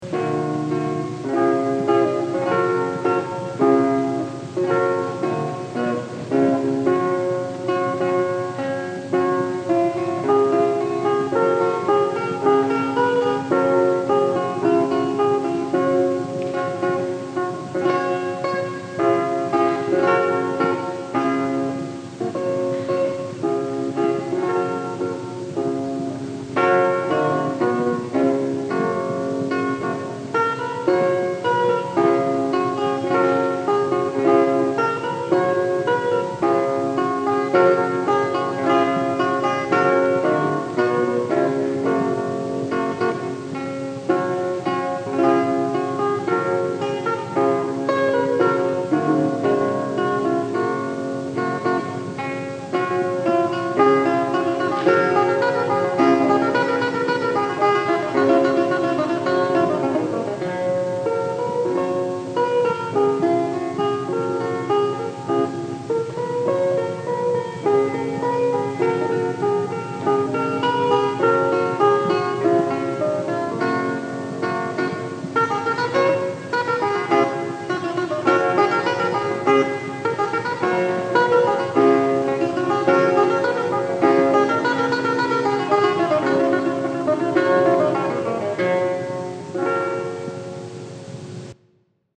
lute